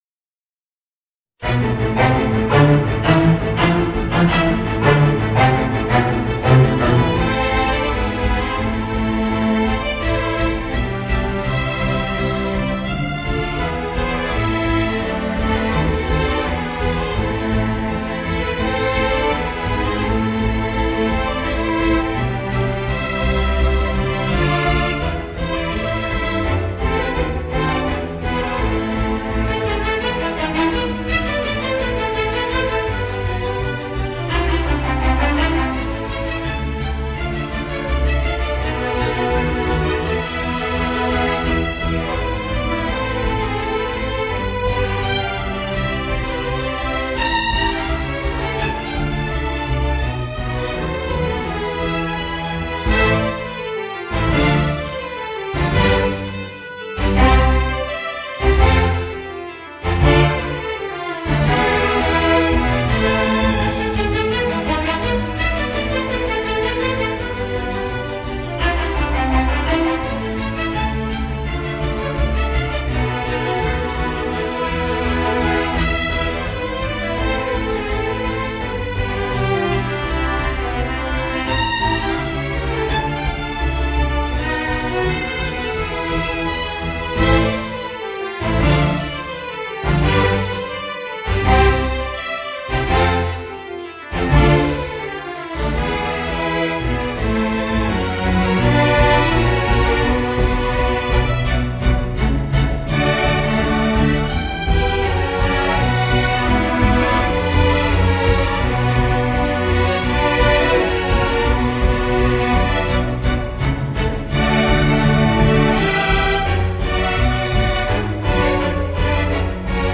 Voicing: String Quintet